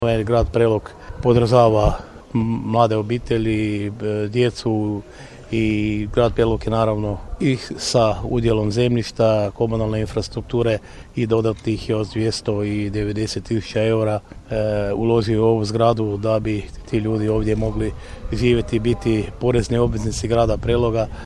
Ključeve su vlasnicima predali ministar prostornoga uređenja, graditeljstva i državne imovine Branko Bačić i direktor Agencije za pravni promet i posredovanje nekretninama Dragan Hristov te gradonačelnik Grada Preloga Ljubomir Kolarek koji je istaknuo: